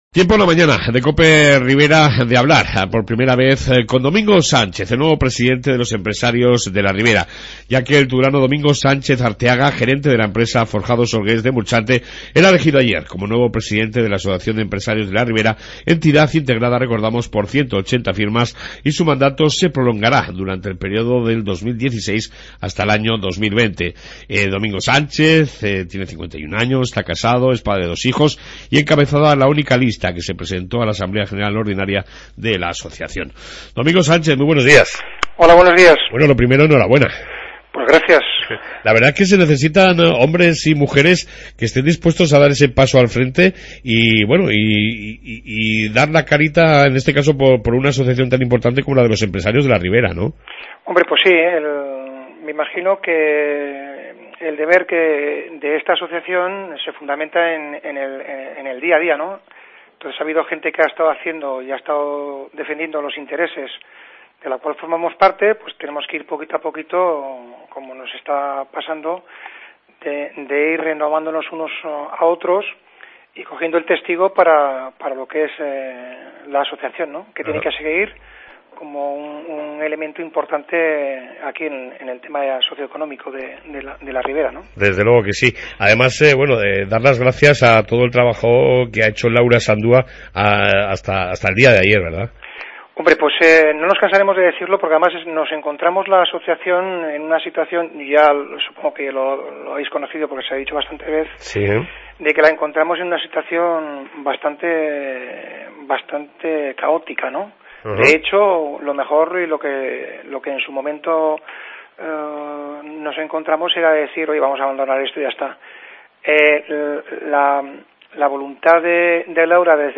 AUDIO: Amplia entrevista